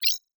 Holographic UI Sounds 94.wav